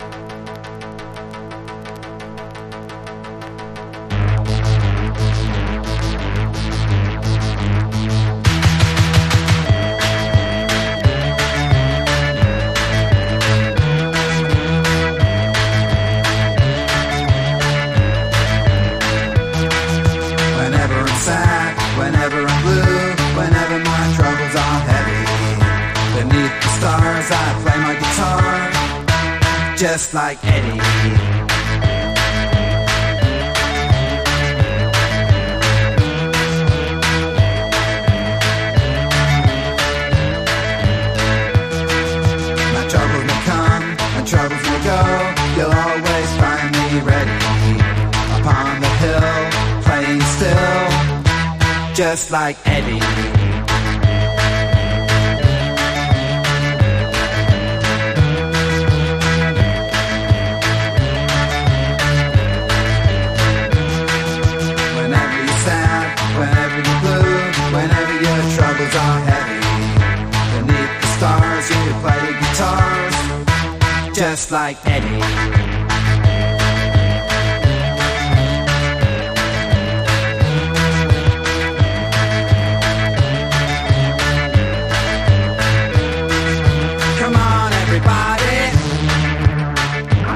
キュートな電子音＋脱力ヴォーカルの中毒性は相当なもの。